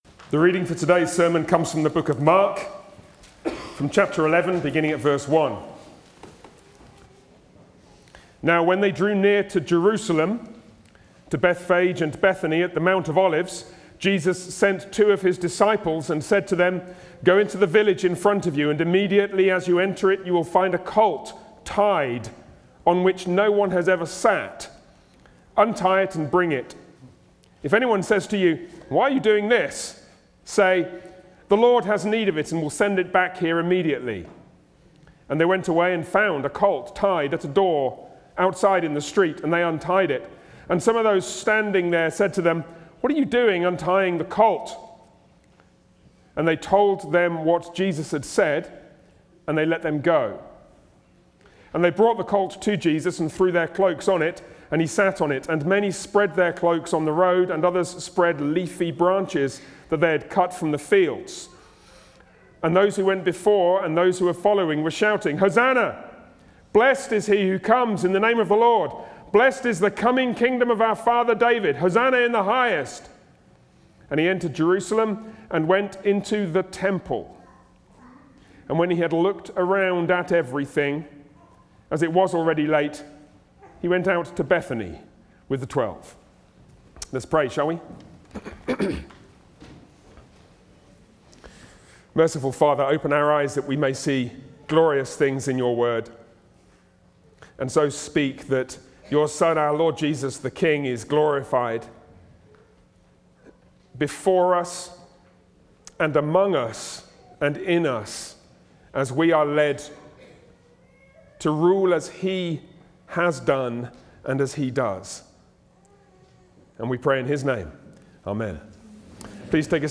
Sermons on the Gospel of Mark
Service Type: Sunday worship